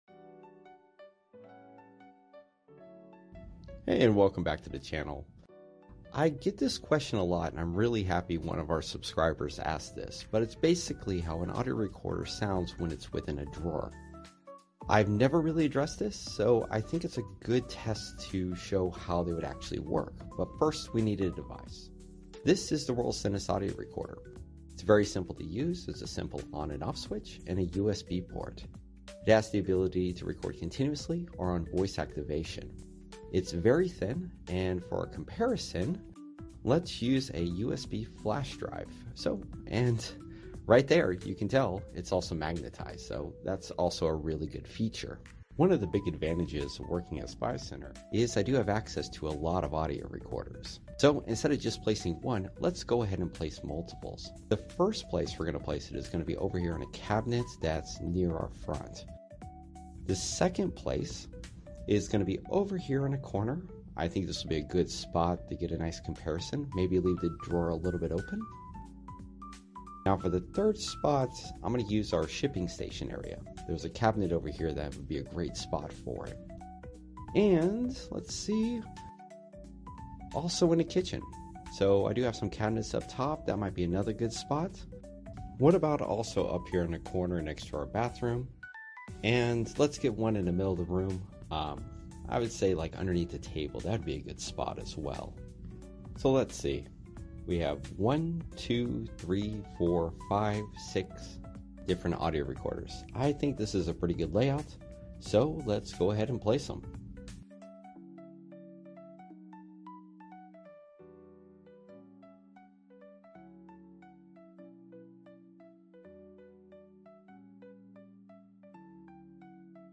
Have you ever wondered how sound effects free download By spycentresecurity 1 Downloads 6 months ago 408 seconds spycentresecurity Sound Effects About Have you ever wondered how Mp3 Sound Effect Have you ever wondered how good the audio quality is when a hidden audio recorder is stashed inside a cabinet, drawer, or even under a table? Well, I put it to the test, and the results were not what you would suspect!